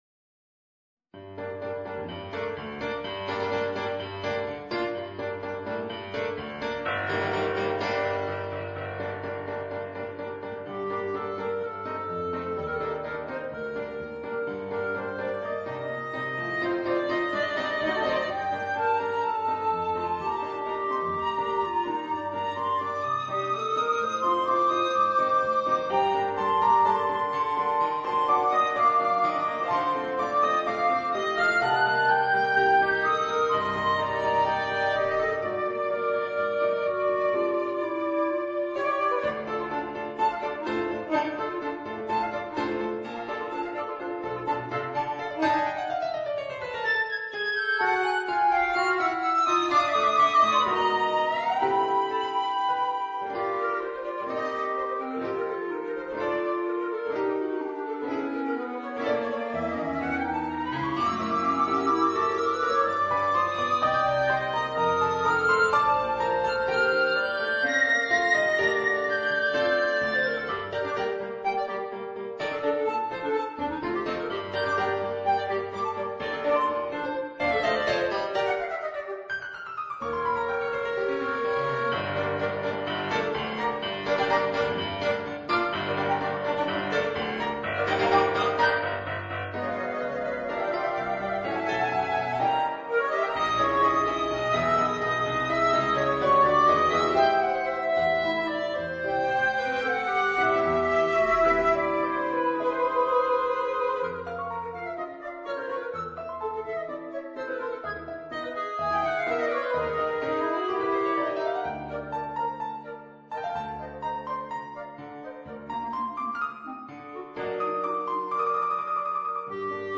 per flauto, clarinetto e pianoforte